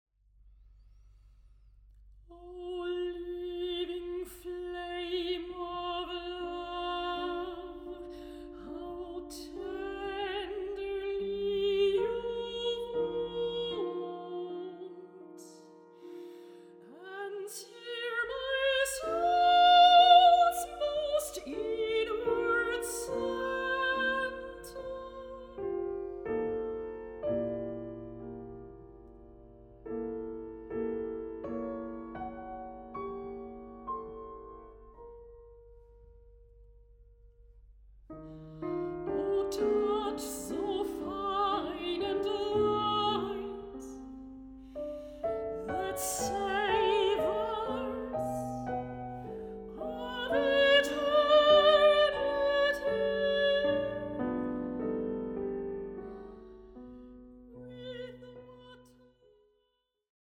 Mezzo-soprano
piano
Recording: Mendelssohn-Saal, Gewandhaus Leipzig, 2025